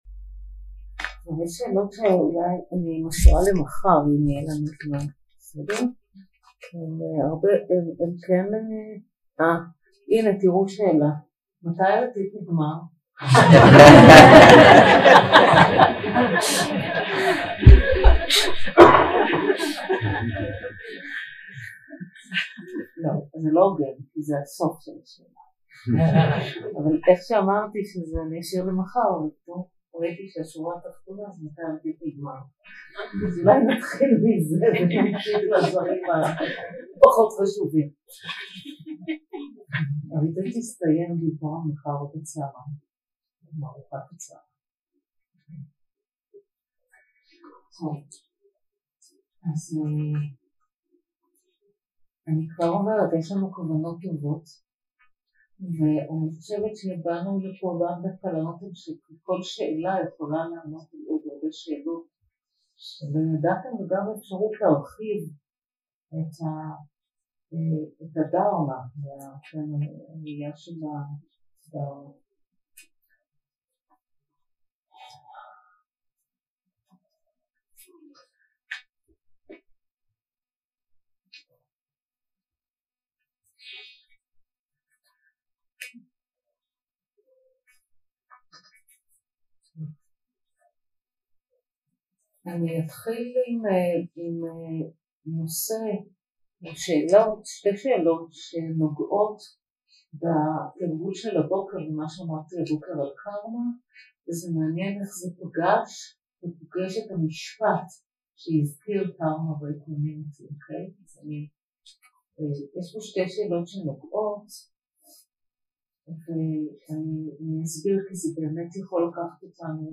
יום 5 – הקלטה 15 – ערב – שאלות ותשובות – חמלה במלחמה ושאלות אחרות 0:00 0:00 סוג ההקלטה: Dharma type: Questions and Answers שפת ההקלטה: Dharma talk language: Hebrew